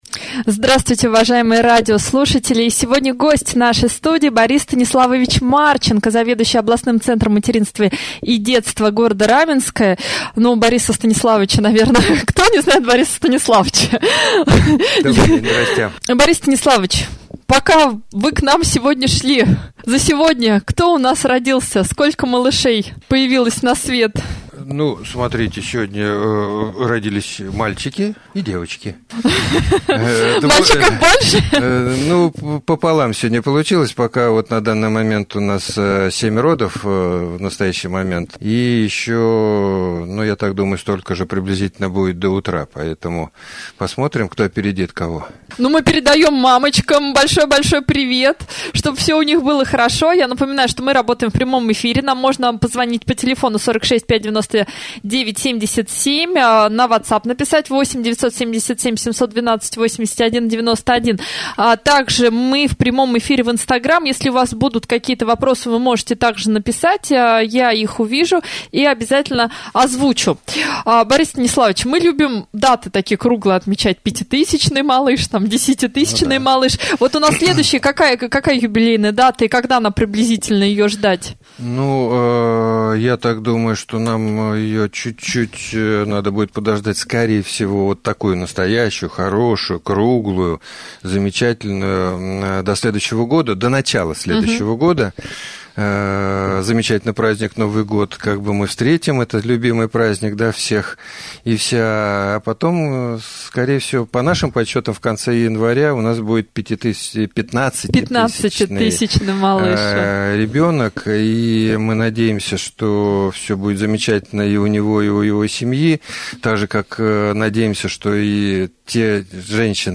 prjamoj-jefir-3.mp3